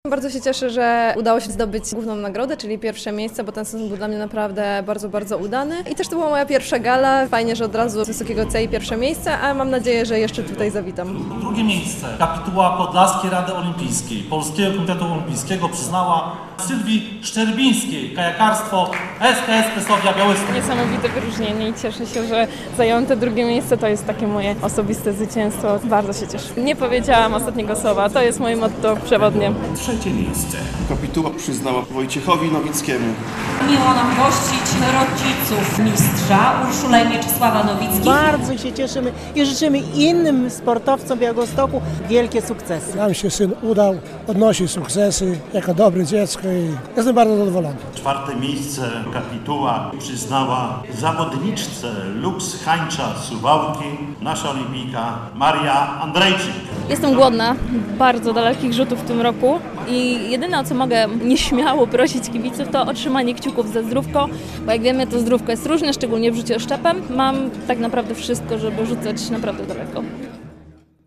W Hotelu Gołębiewski w Białymstoku zorganizowano 27. Podlaską Galę Olimpijską - najbardziej prestiżowy ranking sportowy w województwie podlaskim.